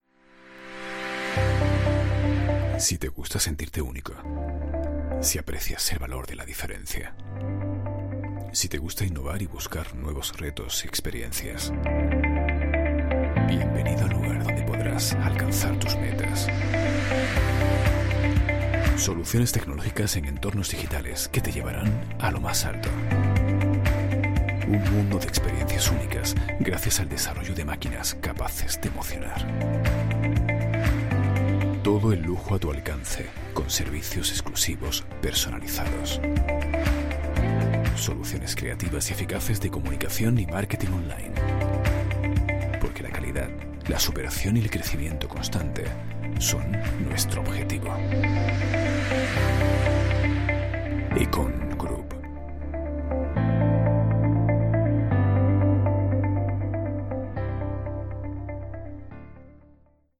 voz cálida, envolvente, elegante, transmite confianza, seguridad, seriedad con sentido de humor, versátil, seductora
Sprechprobe: Industrie (Muttersprache):
Corporativo_0.mp3